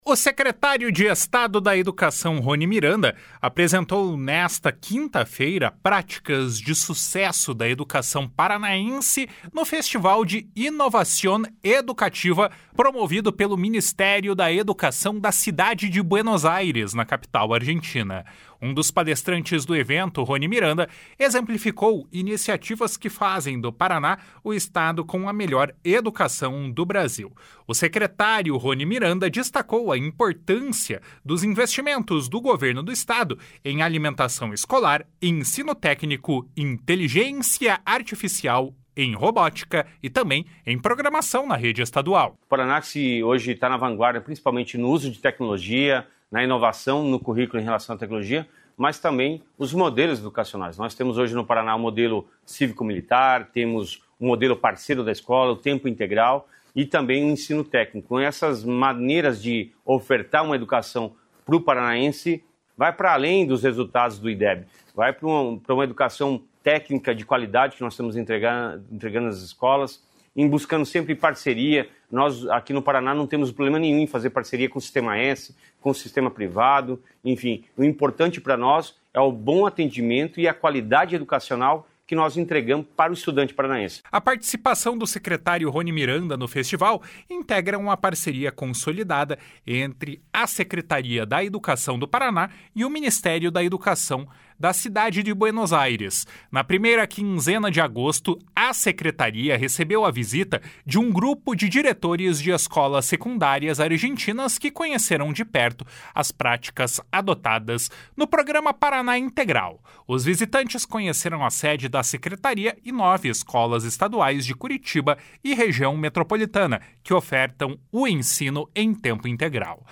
O secretário destacou a importância dos investimentos do governo do Estado em alimentação escolar, ensino técnico, inteligência artificial, robótica e programação na rede estadual. // SONORA RONI MIRANDA //